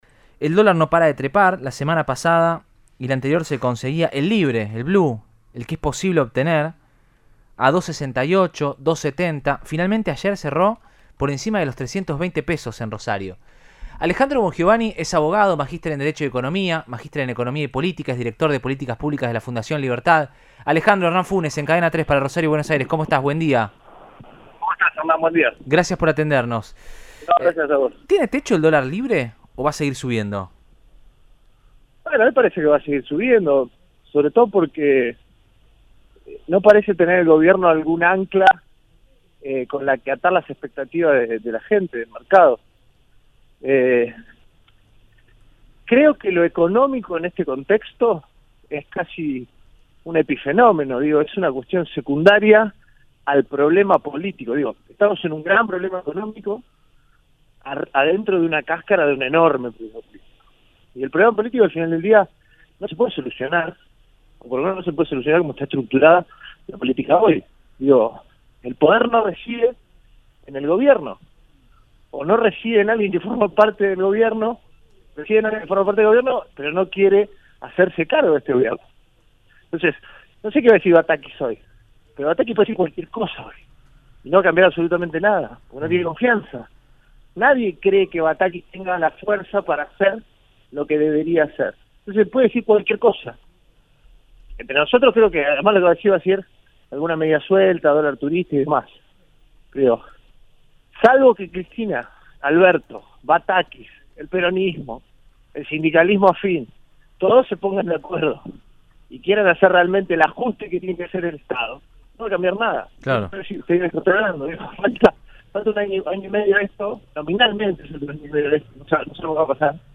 dialogó con Radioinforme 3 de Cadena 3 Rosario sobre la actualidad económica y política de país a en la previa de los anuncios de la ministra de economía Silvina Batakis.